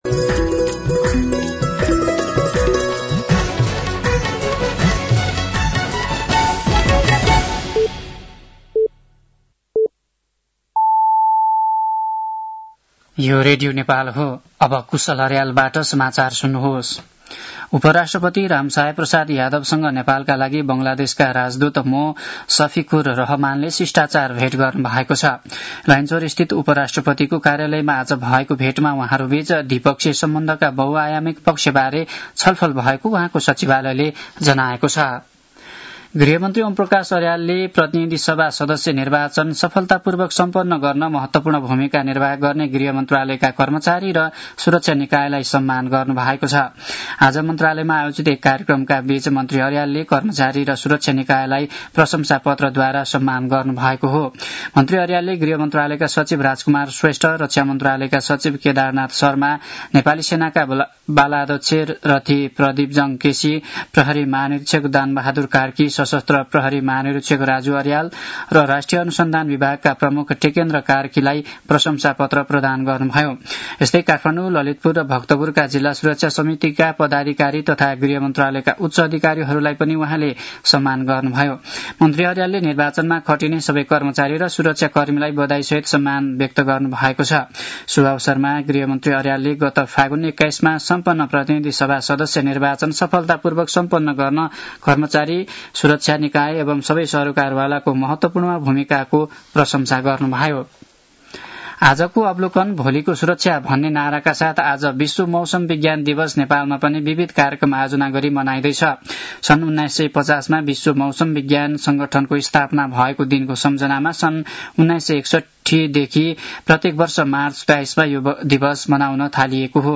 साँझ ५ बजेको नेपाली समाचार : ९ चैत , २०८२